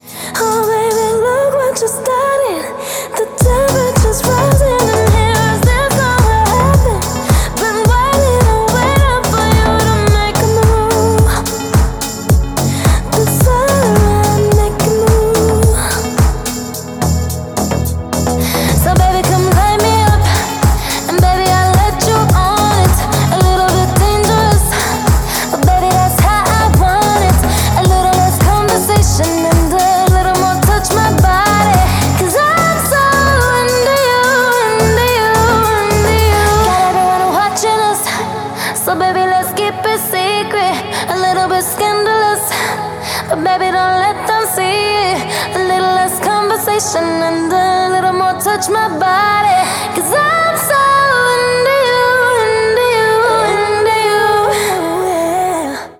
красивый женский голос
Нормальный рингтон в стиле deep house